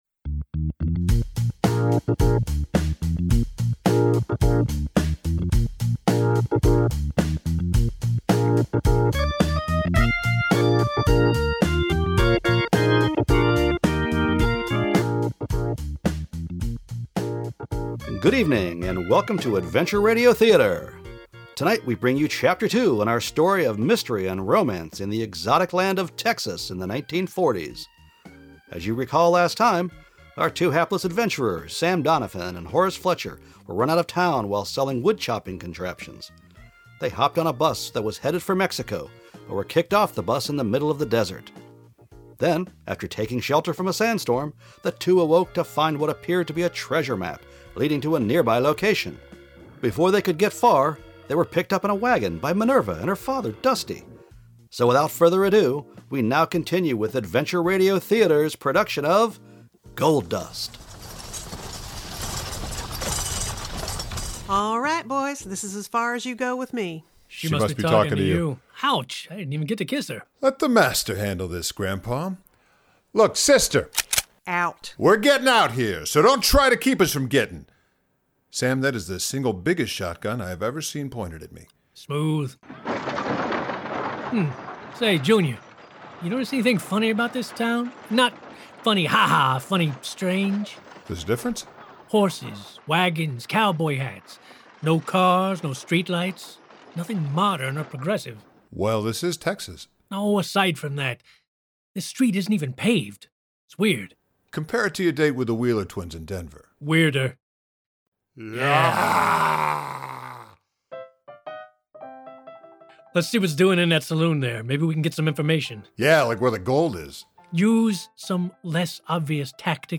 Gold Dust: A Comic Western Radio Play